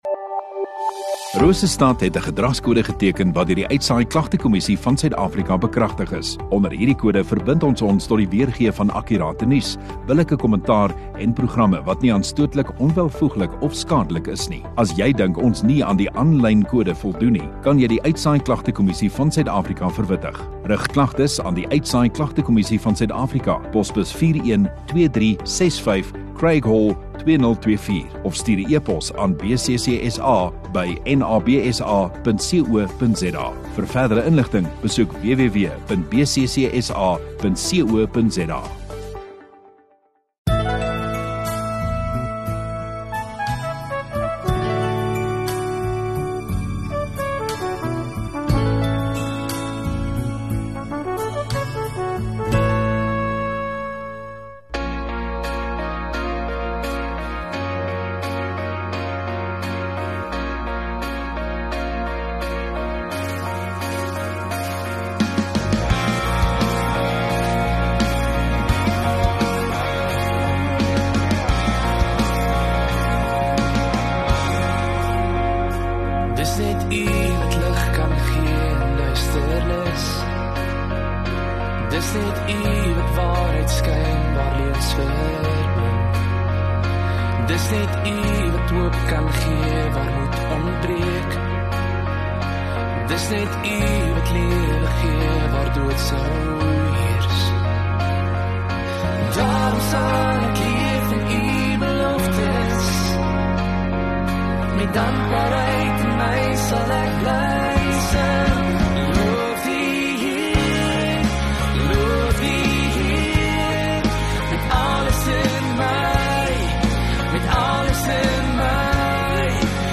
13 Jul Sondagoggend Erediens